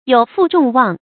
有負眾望 注音： ㄧㄡˇ ㄈㄨˋ ㄓㄨㄙˋ ㄨㄤˋ 讀音讀法： 意思解釋： 辜負眾人的期望。